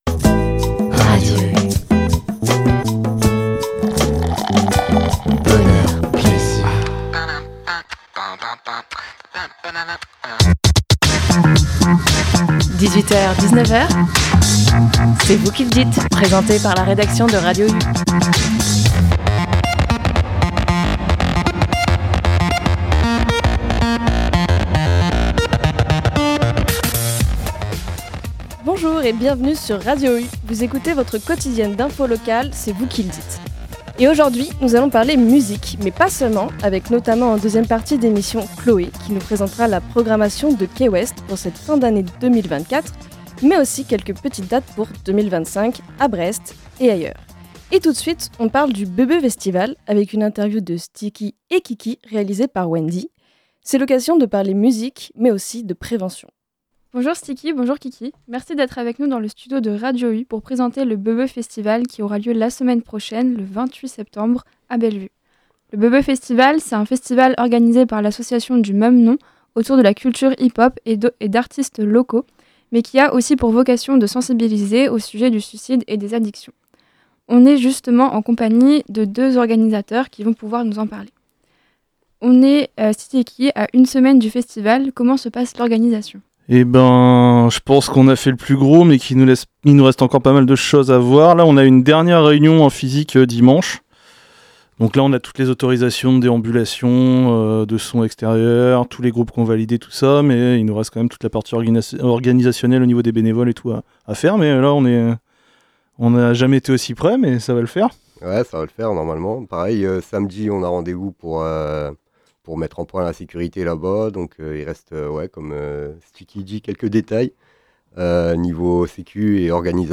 De la musique, toujours de la musique, mais de la prévention aussi.